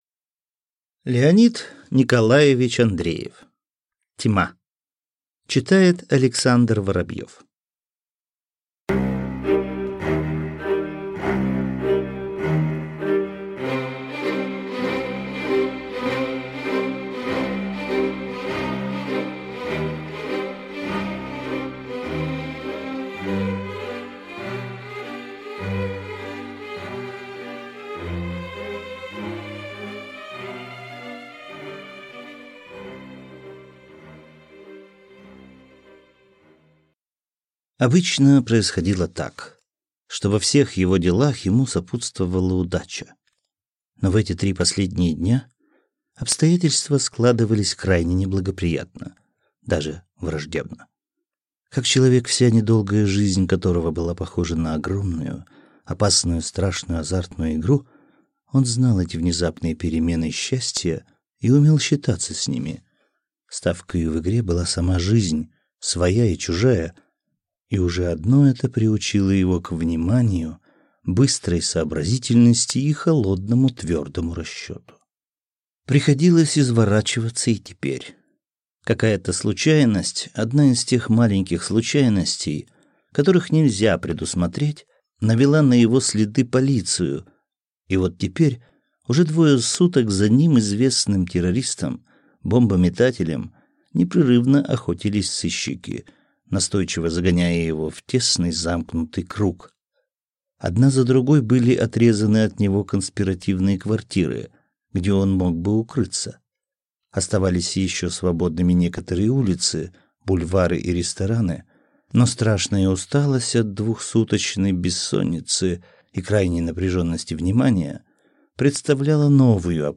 Аудиокнига Тьма | Библиотека аудиокниг